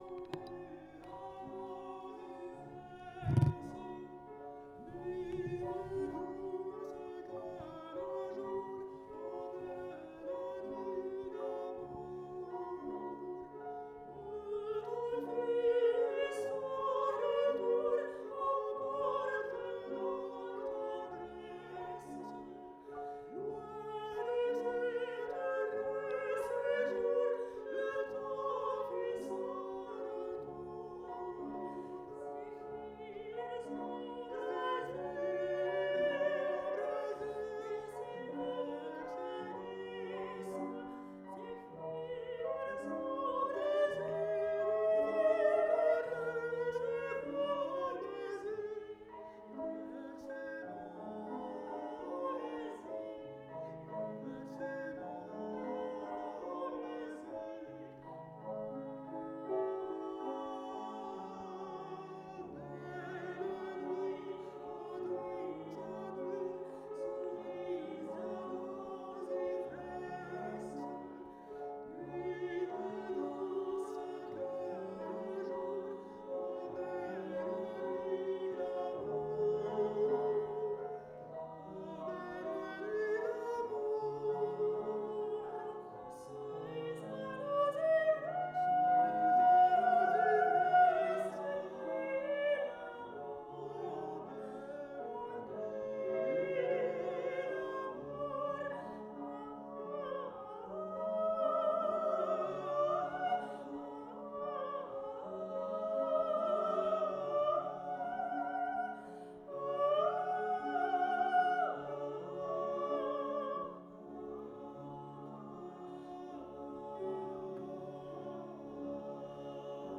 Performance in 2020
August 28, 2020, Moniuszko Festival, Poland